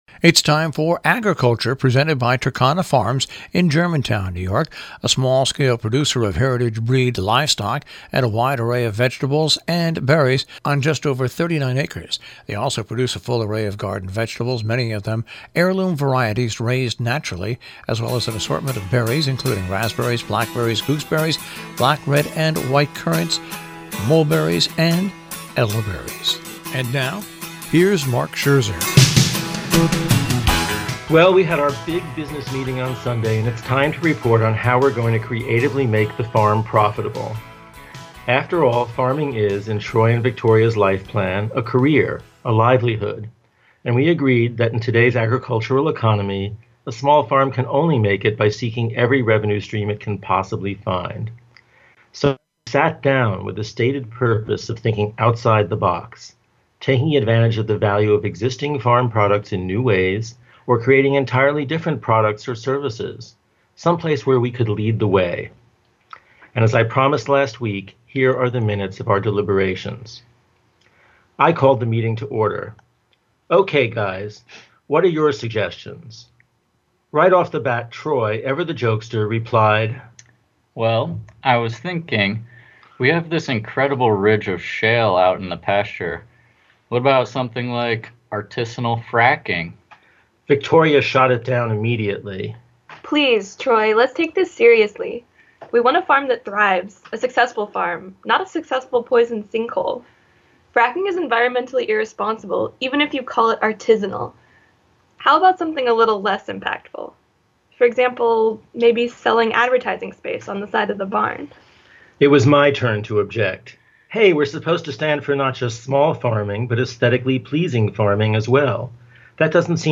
HEAR OUR SHOWIf you'd enjoy hearing these bulletins out loud instead of reading them, we broadcast them on Robin Hood Radio, the nation's smallest NPR station.